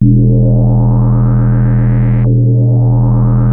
JUP 8 E3 9.wav